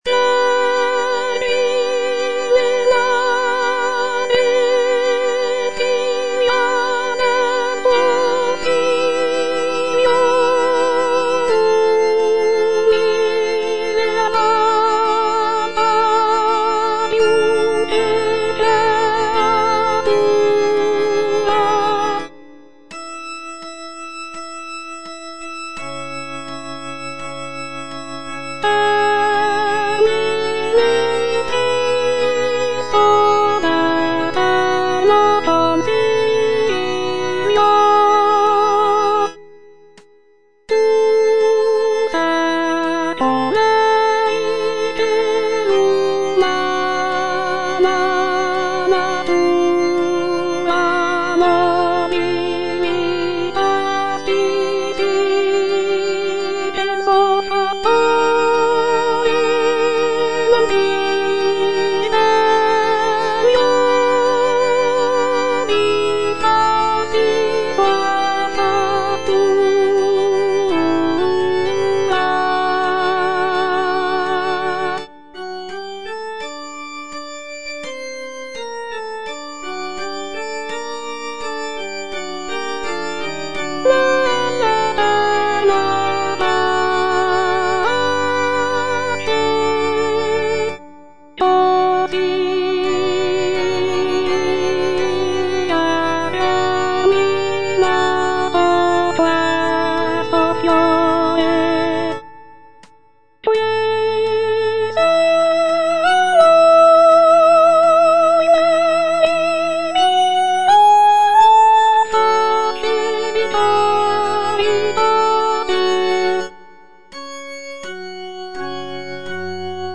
G. VERDI - LAUDI ALLA VERGINE MARIA Soprano II (Voice with metronome) Ads stop: auto-stop
"Laudi alla Vergine Maria" is a sacred choral work composed by Giuseppe Verdi as part of his "Quattro pezzi sacri" (Four Sacred Pieces). It is a hymn of praise to the Virgin Mary, with text inspired by Dante's Divine Comedy. The piece features lush harmonies and intricate counterpoint, showcasing Verdi's mastery of choral writing.